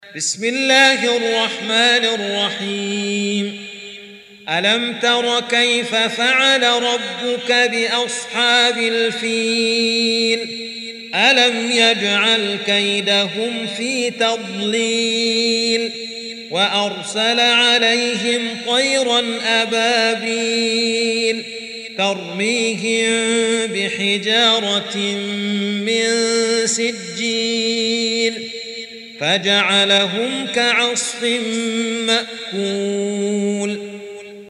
Surah Sequence تتابع السورة Download Surah حمّل السورة Reciting Murattalah Audio for 105. Surah Al-F�l سورة الفيل N.B *Surah Includes Al-Basmalah Reciters Sequents تتابع التلاوات Reciters Repeats تكرار التلاوات